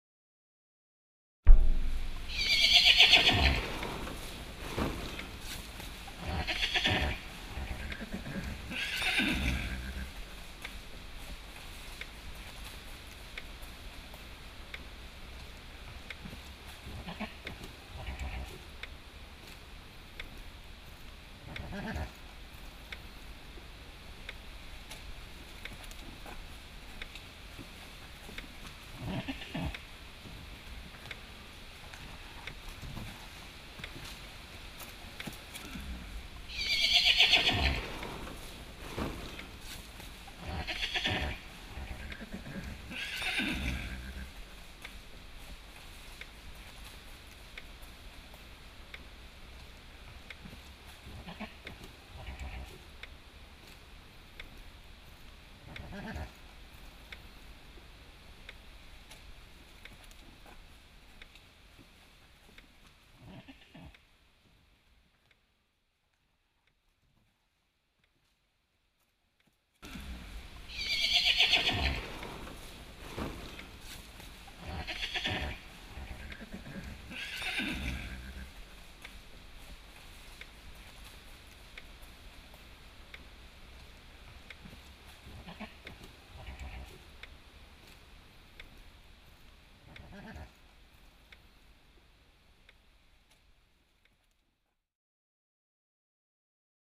ponie asturcon.mp3